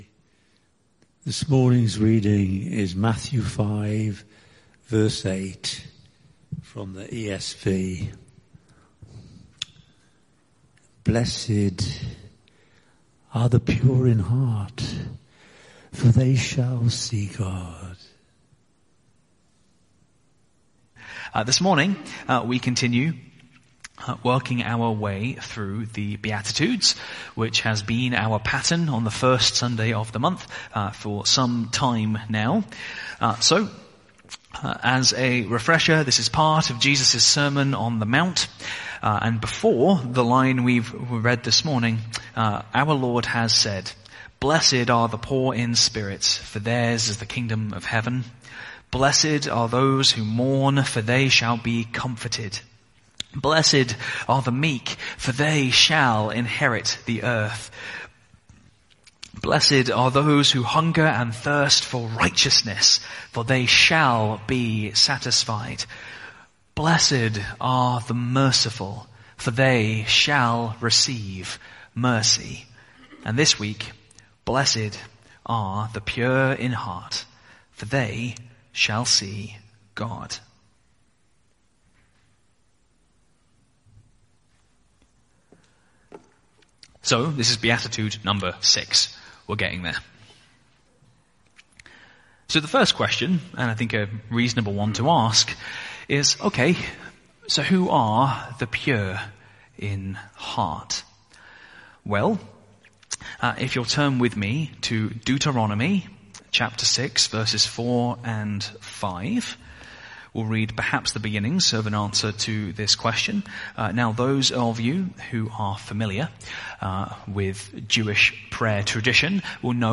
Sermon Series: Beatitudes | Sermon Title: Blessed are the Pure in Heart